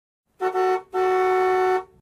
gudok_avto.ogg